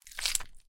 squish02.ogg